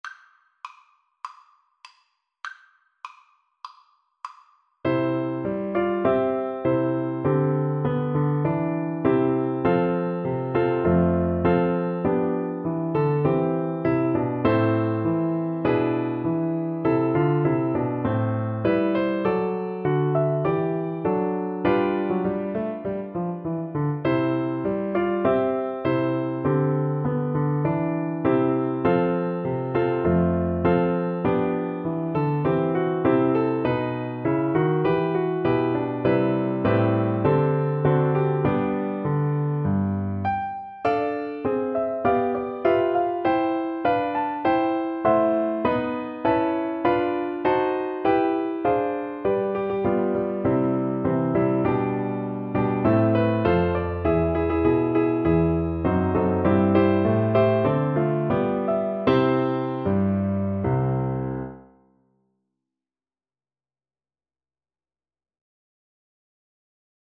irish_nat_anth_REC_kar1.mp3